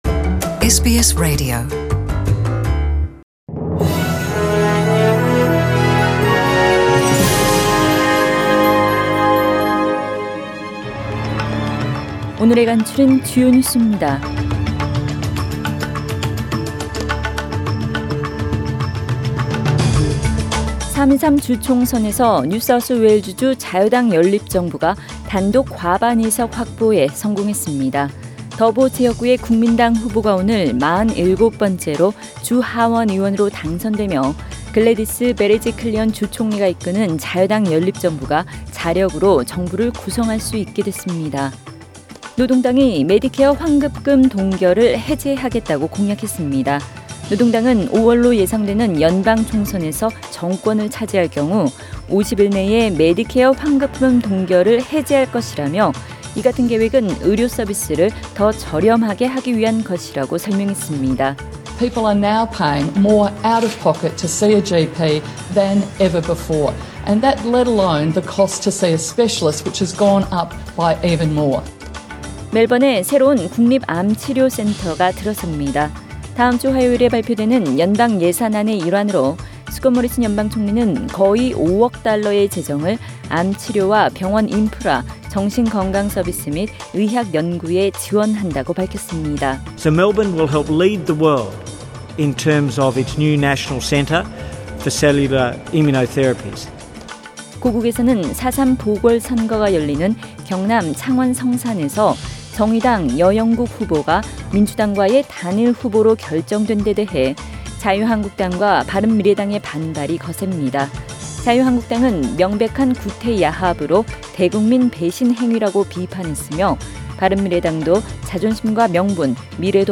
SBS 한국어 뉴스 간추린 주요 소식 – 3월 25일 월요일